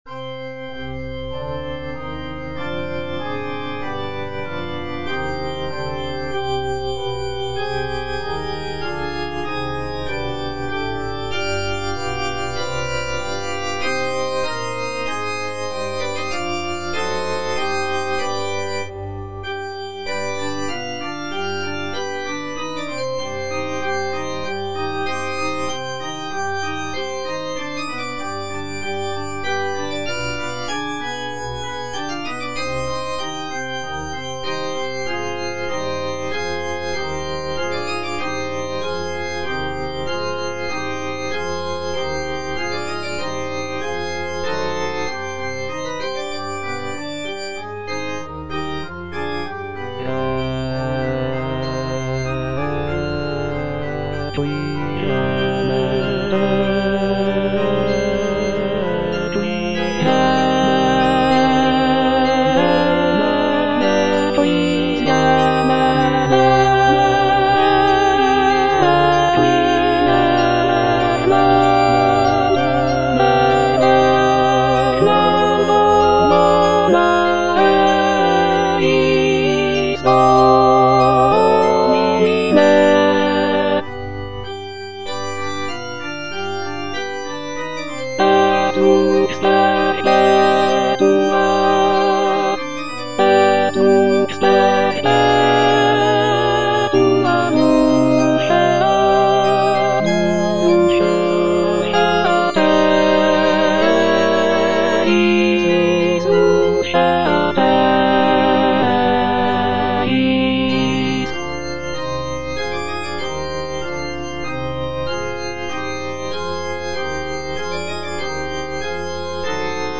Alto (Emphasised voice and other voices) Ads stop
is a choral composition